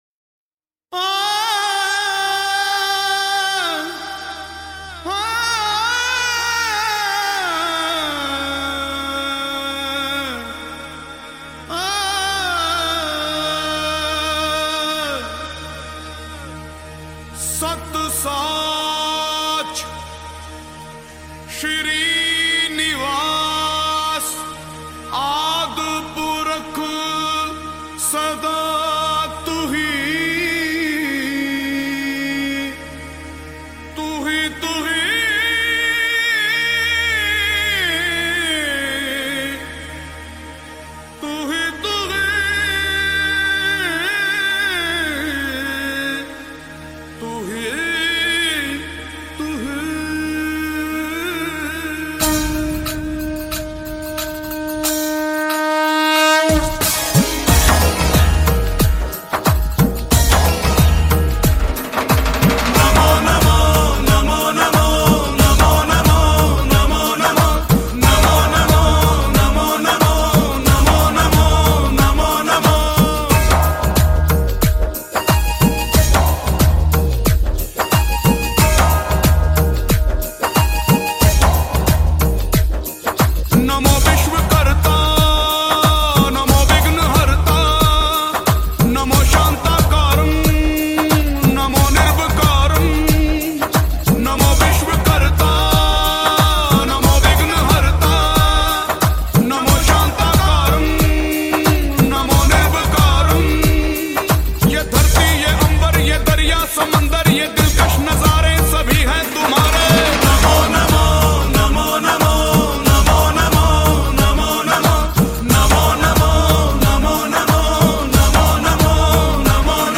Bhakti Songs